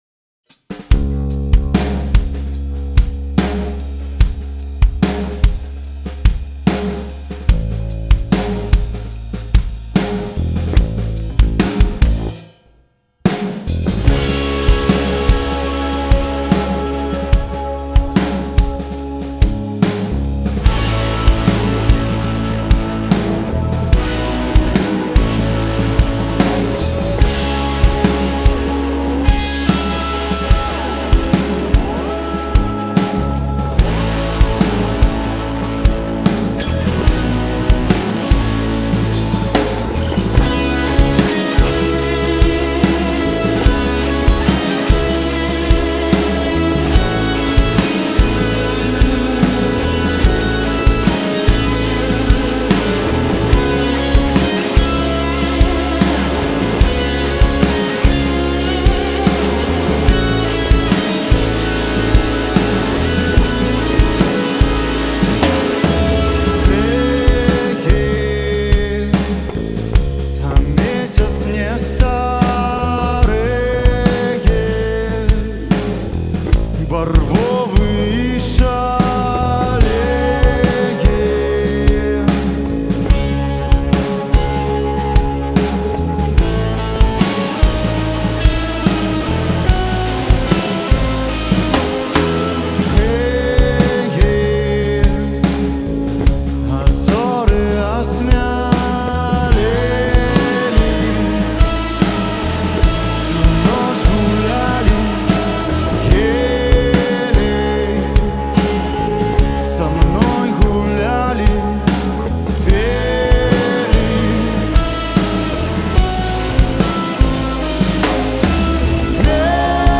які быў запісаны жыўцом у студыі праграмы